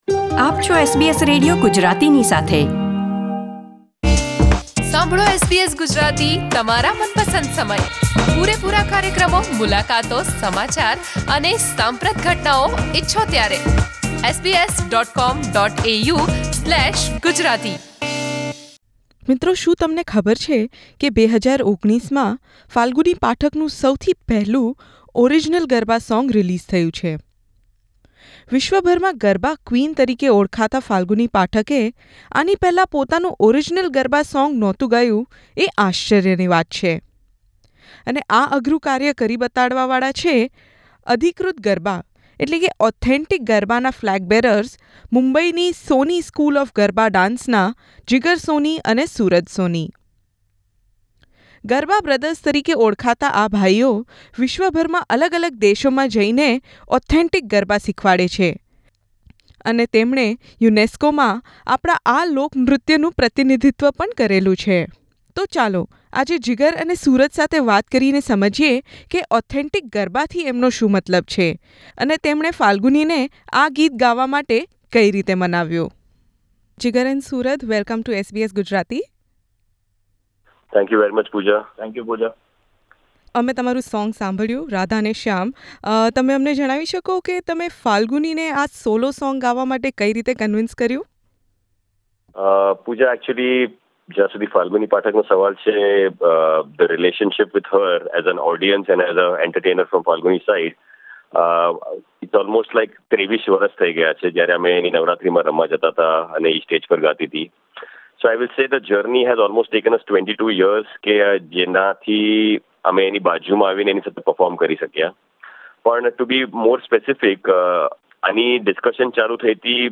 કરેલી મુલાકાત